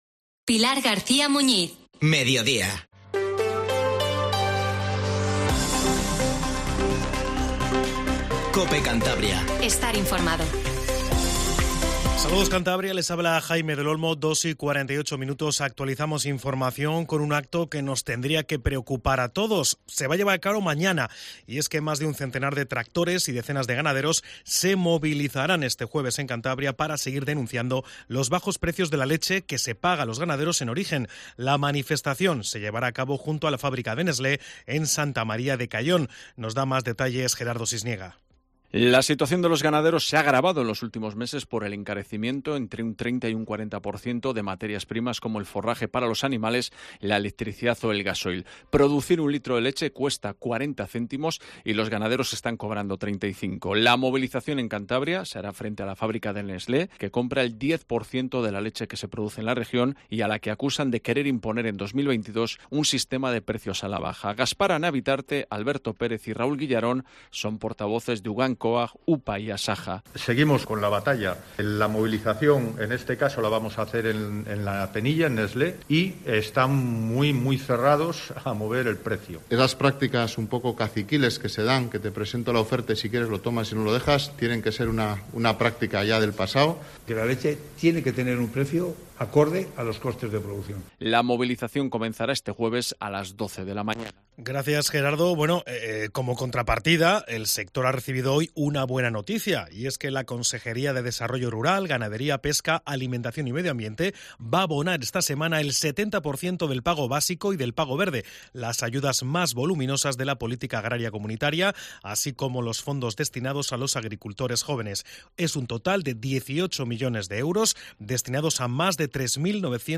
Informativo Mediodía COPE CANTABRIA